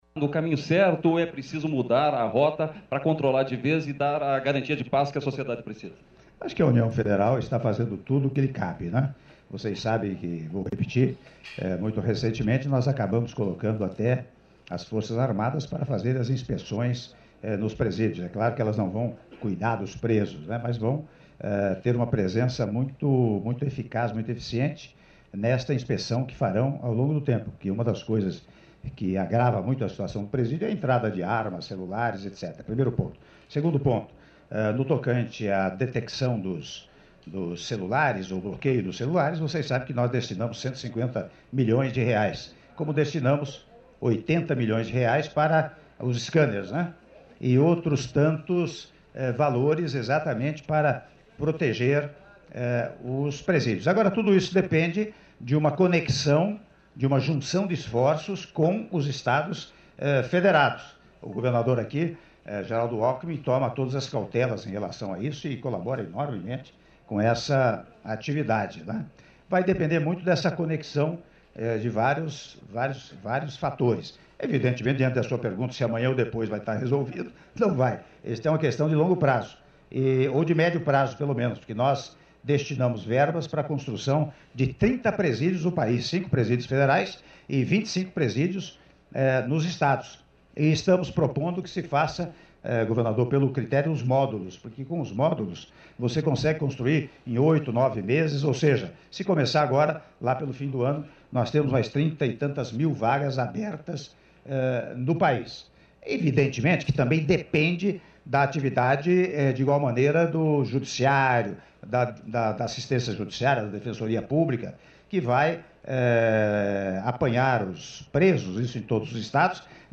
Áudio da entrevista do presidente da República, Michel Temer, após cerimônia de Lançamento do Pré-Custeio da Safra 2017/2018 - Ribeirão Preto/SP (05min33s) — Biblioteca